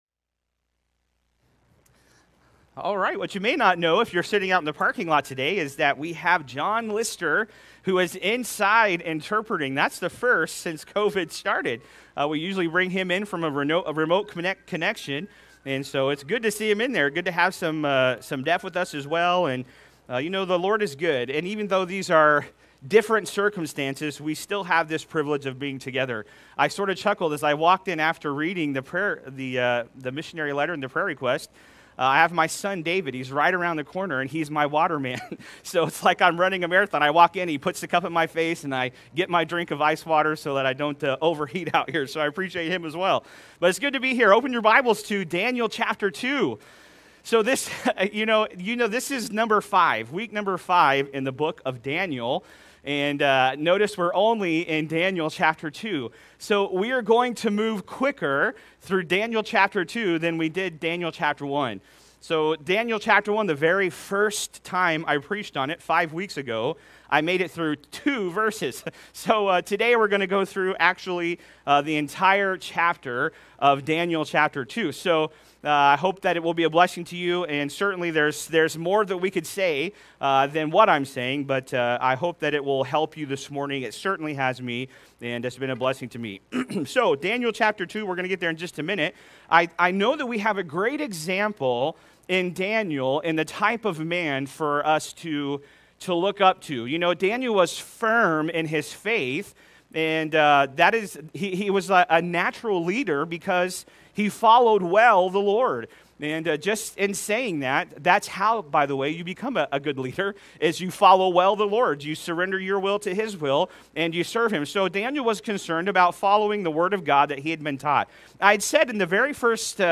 Sermons | New Testament Baptist Church